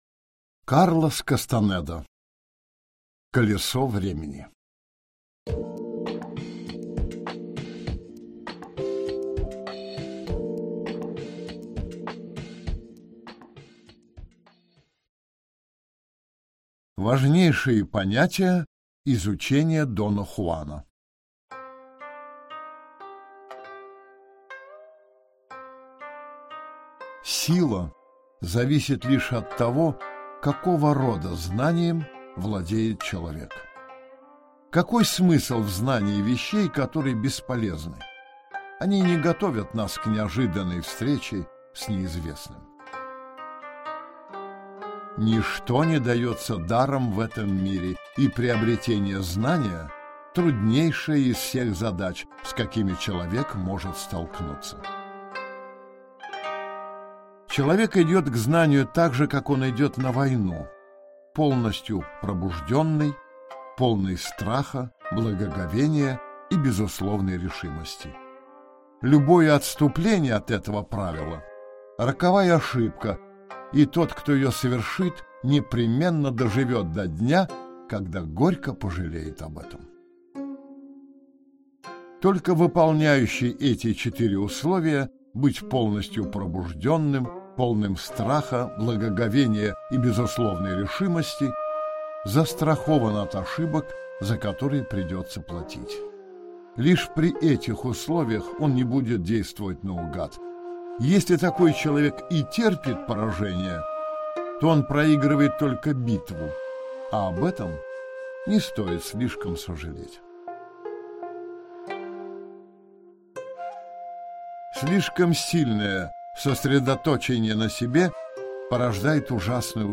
Аудиокнига Колесо времени | Библиотека аудиокниг